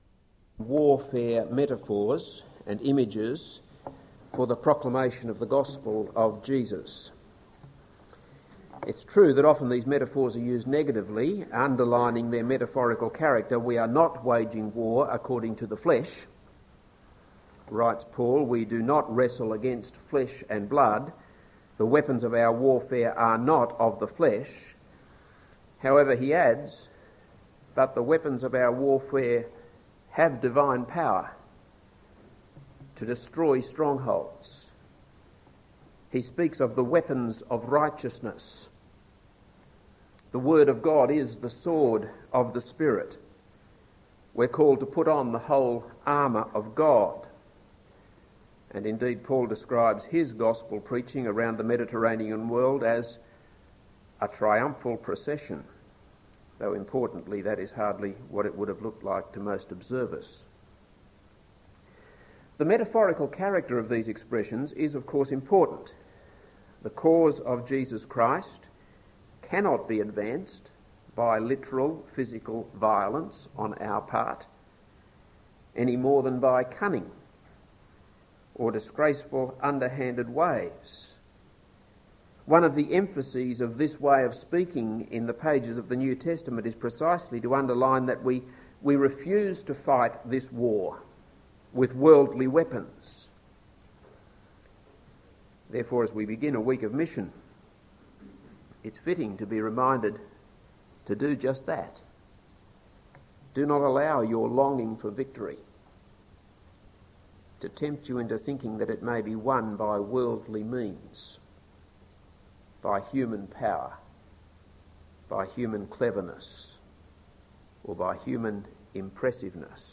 This is a sermon on 1 Samuel 11.